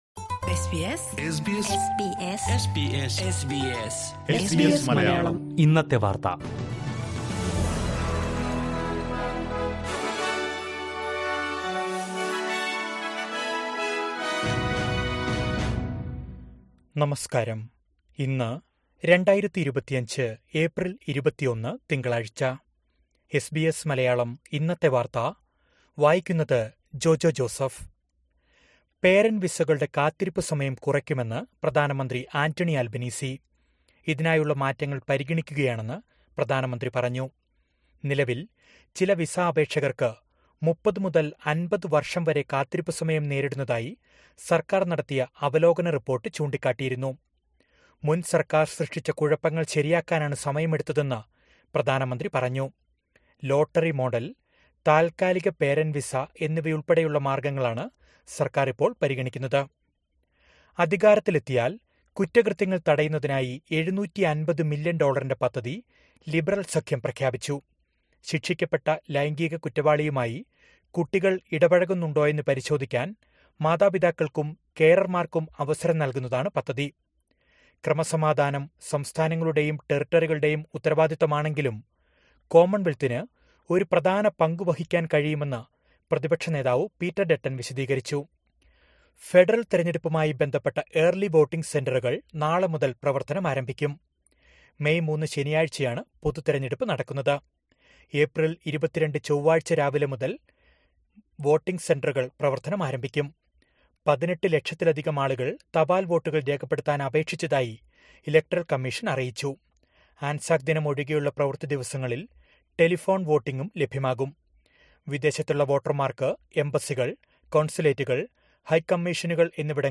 2025 ഏപ്രില്‍ 21ലെ ഓസ്‌ട്രേലിയയിലെ ഏറ്റവും പ്രധാന വാര്‍ത്തകള്‍ കേള്‍ക്കാം...